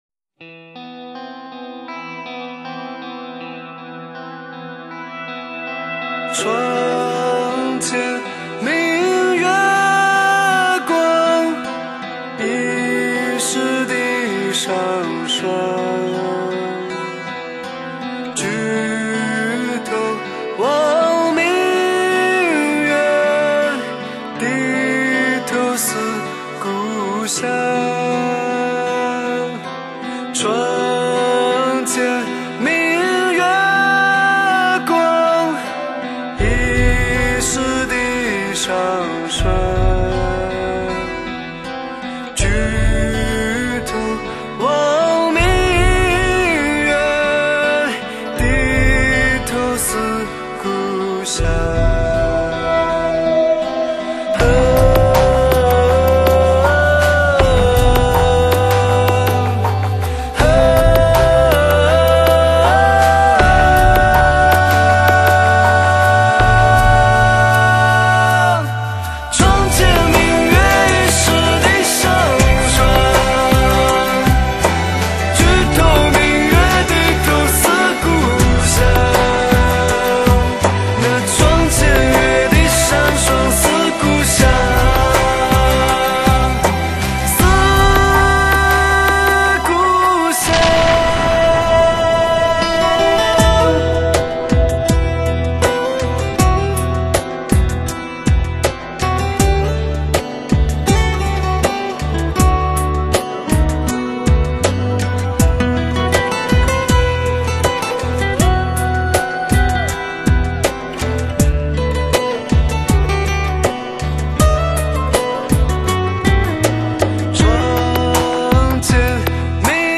三个来自湖南的男孩，远赴他乡寻找自己的音乐梦想。
歌曲略带幽幽 的伤感，旨在带听众跟着主人公的倾诉进入到另一个情感的空间，清淡言语，浓浓的情意，是谁的心弦被拨动了？